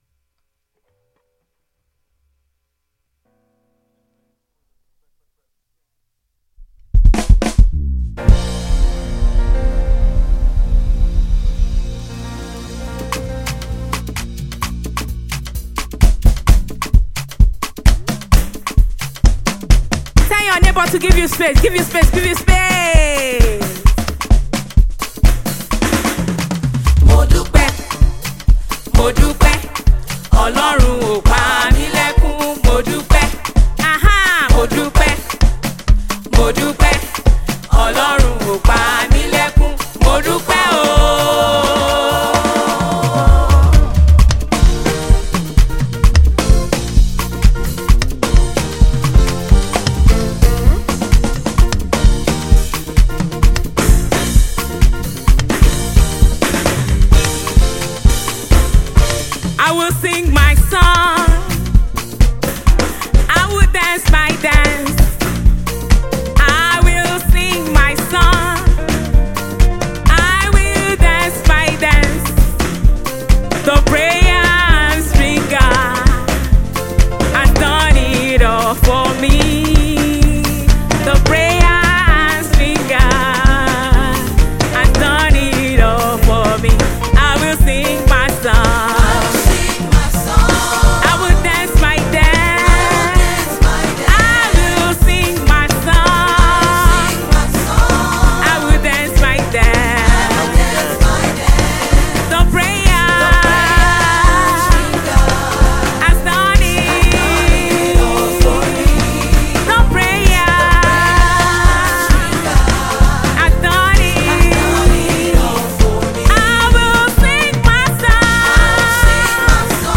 Nigerian gospel